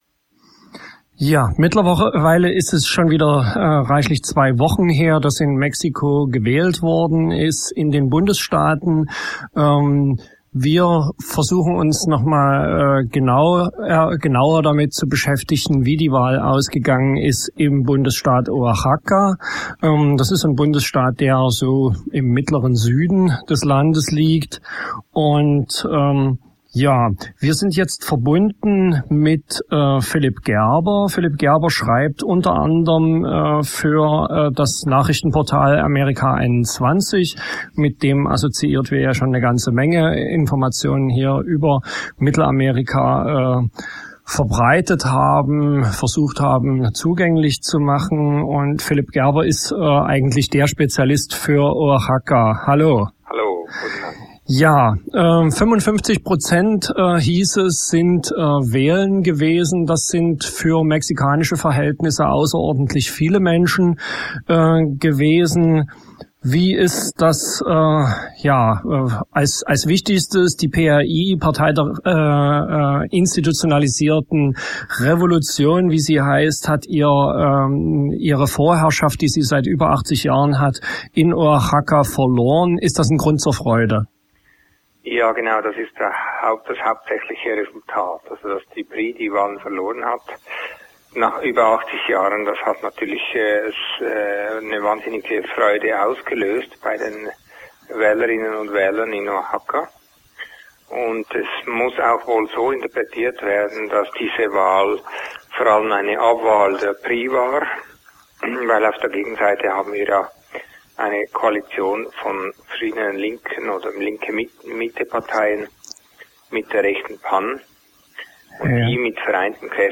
03.11.2009 Interview Frauenkooperativen, Kaffeehandel und selbstverwaltetes Wirtschaften in Chiapas/ Mexiko